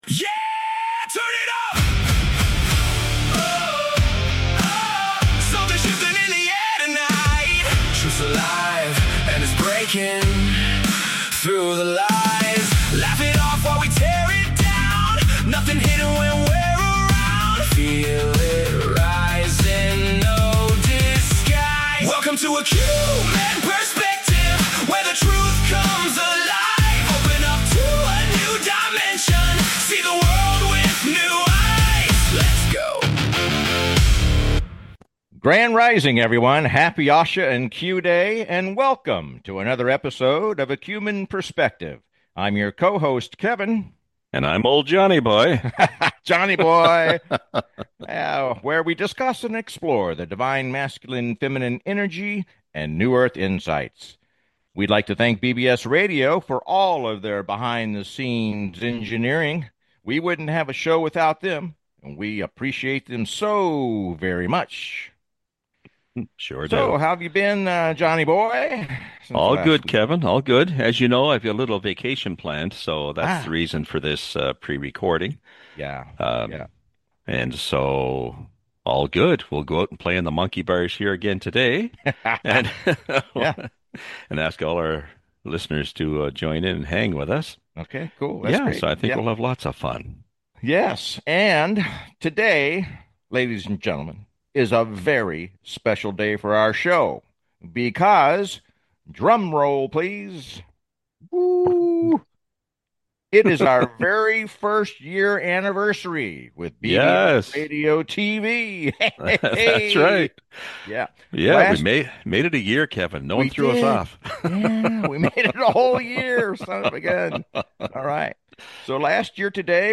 Talk Show Episode, Audio Podcast, A Qmen Perspective and A Q-Men Perspective: The Great Awakening and the Galactic Shift on , show guests , about The Great Awakening,the Galactic Shift,Exploring Divine Energies,Alien Disclosure,the Movie to Save the World,Two Hearts Revelation,Ego Mind,Live in Peace,Deprogramming,Operation Warp Speed, categorized as Comedy,Education,Health & Lifestyle,Paranormal,Philosophy,Psychology,Self Help,Society and Culture,Spiritual